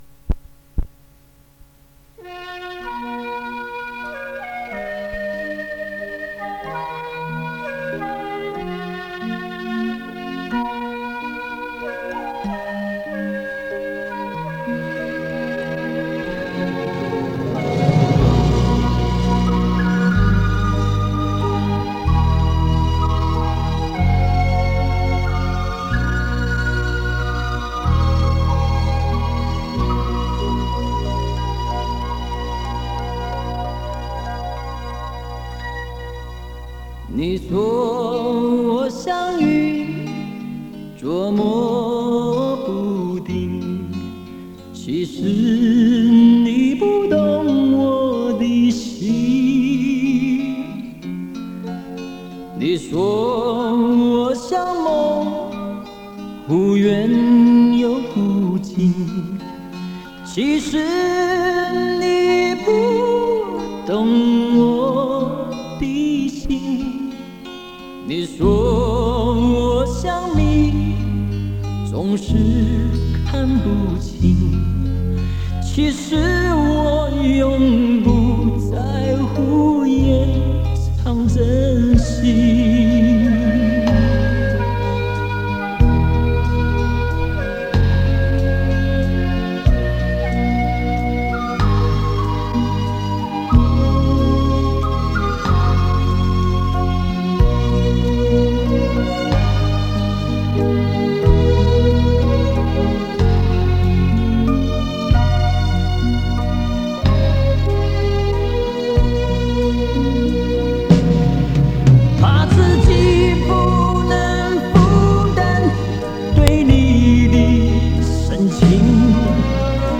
磁带数字化：2022-09-11